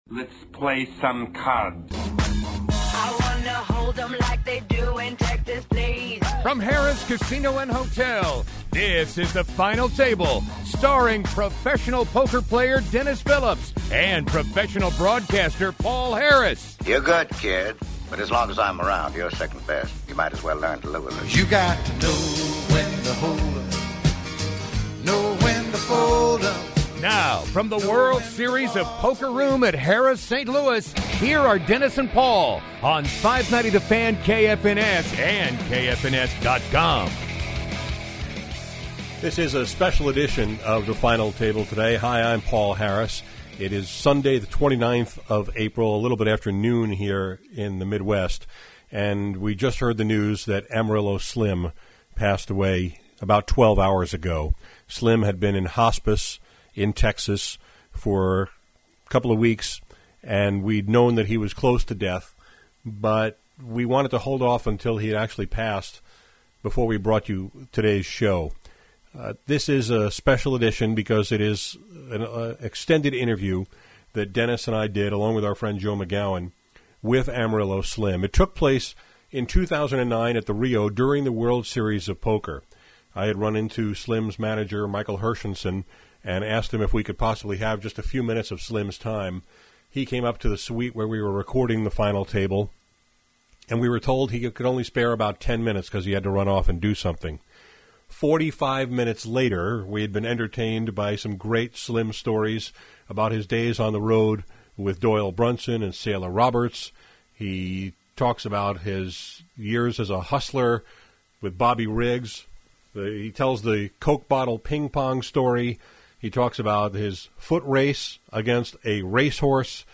In his memory, we’re releasing this special edition of The Final Table poker radio show, consisting of an interview we did with Slim in the summer of 2009 at the Rio in Las Vegas.